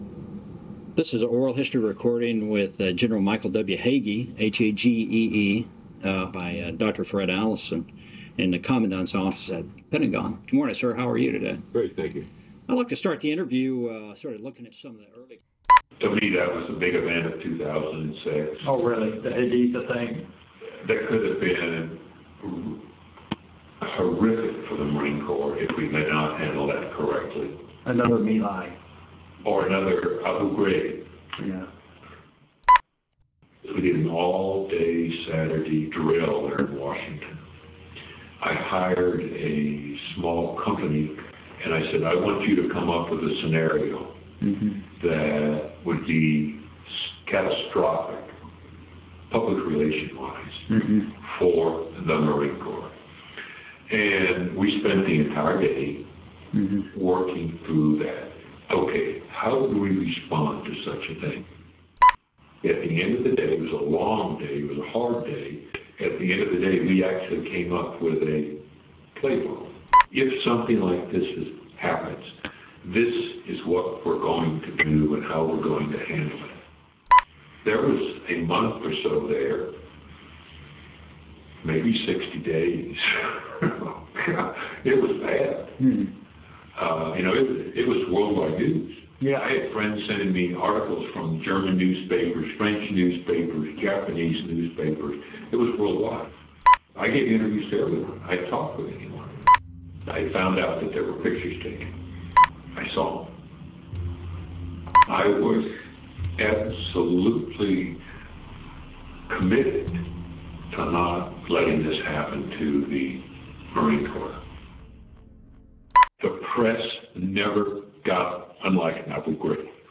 미국 마이클 헤이지 장군이 학살에 대한 해병대의 대외 관계 관리에 대해 논의하고 있다.